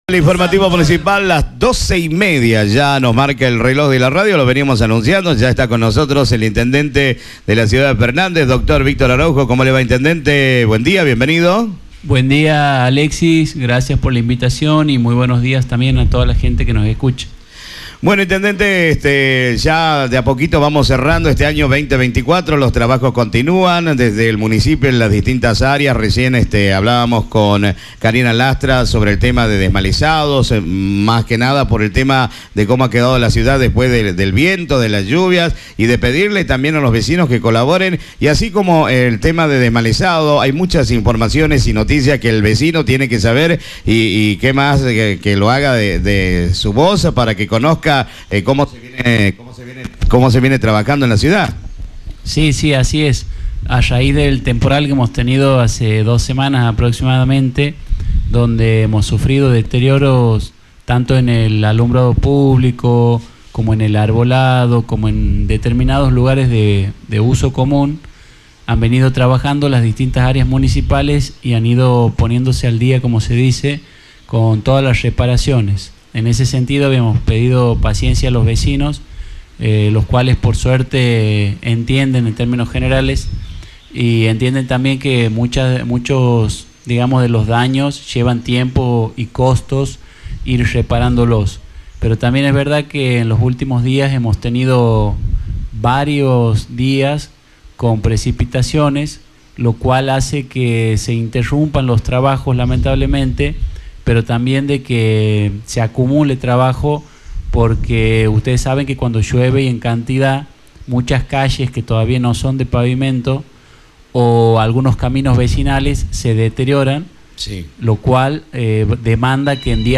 El intendente de la Ciudad de Fernández, Dr. Víctor Araujo, estuvo el pasado sábado en Radio Amistad, donde brindó una entrevista y detalló las actividades que lleva adelante el municipio a su cargo.